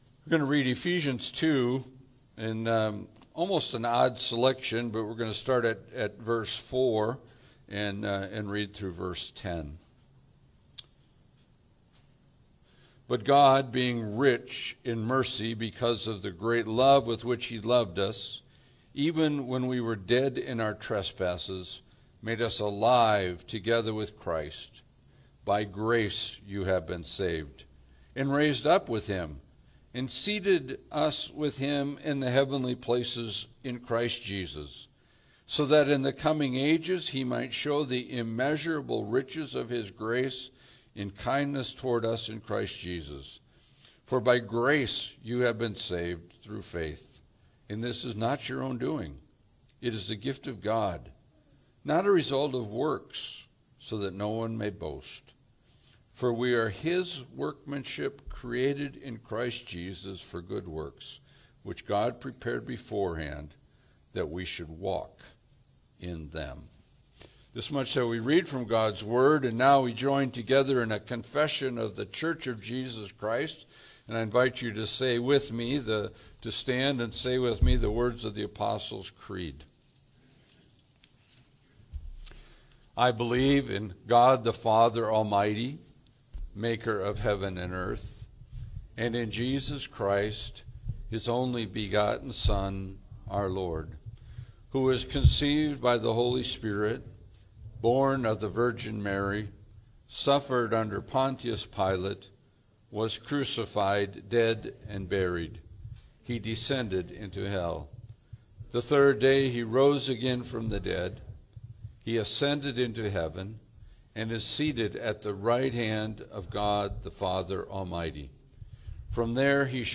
Passage: Ephesians 2:4-10 Service Type: Sunday Service